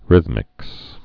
(rĭthmĭks)